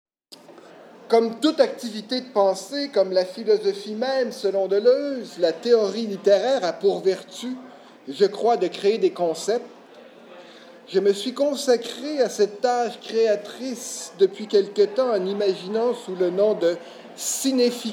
Conférencier(s):